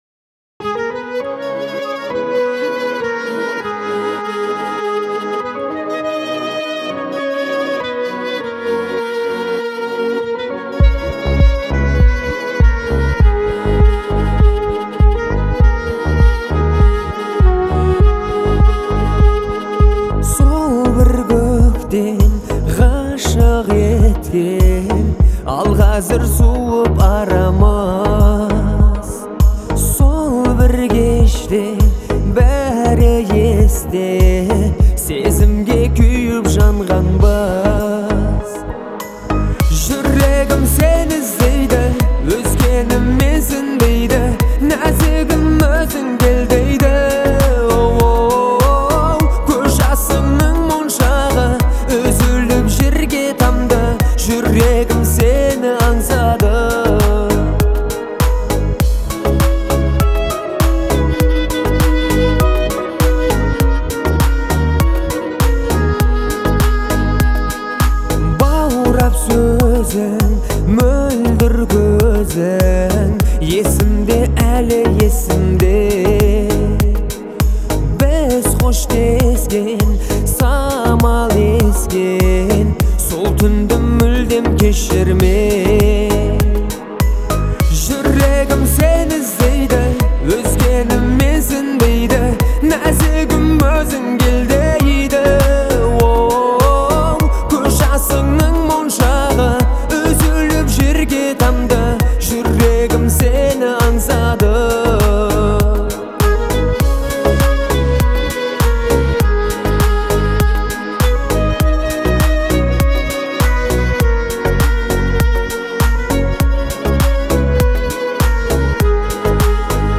это эмоциональная песня в жанре казахской поп-музыки